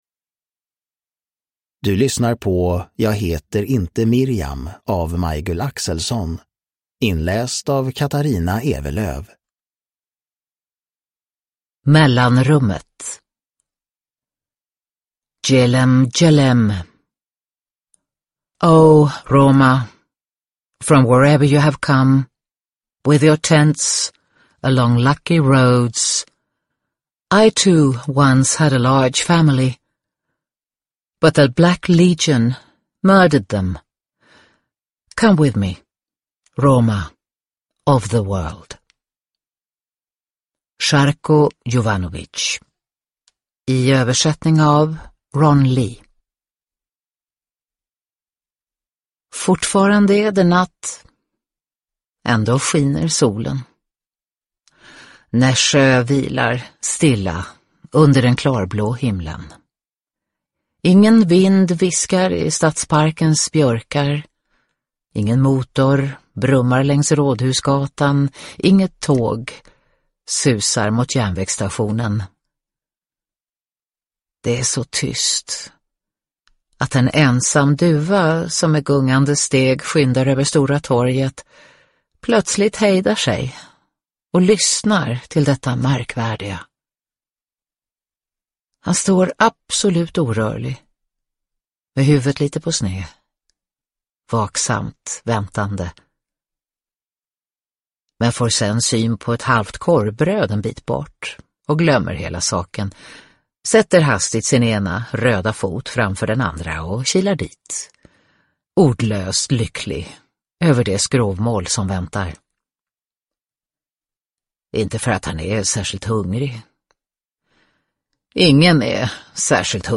Jag heter inte Miriam – Ljudbok – Laddas ner
Uppläsare: Katarina Ewerlöf